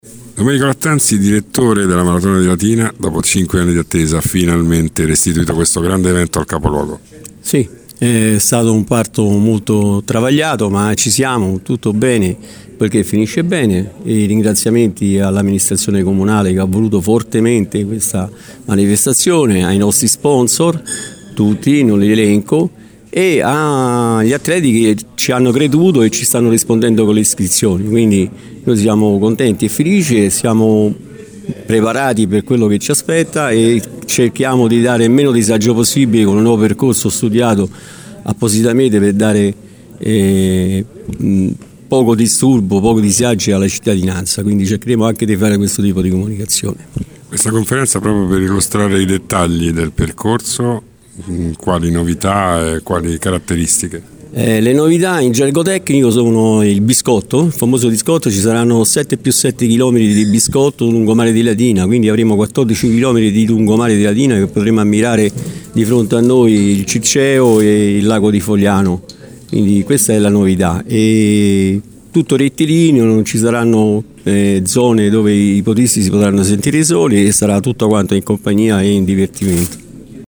Questa mattina presso il Circolo Cittadino di piazza del Popolo si è svolta la conferenza stampa di presentazione della “Maratona di Latina 23.0”, il grande evento organizzato dall’Uisp di Latina in coorganizzazione con il Comune e programmato per domenica 1 dicembre con start alle 9 in piazza San Marco.